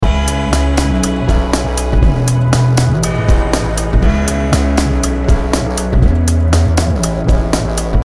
その名の通り、まるでテープに一度録音したかのような温かみのあるLo-Fiサウンドが特徴です。
ドラムとベースは別で用意したものですが、パッド系の音は「MOSAIC TAPE」にて同じ音をオクターブ違いでレイヤーして作っています。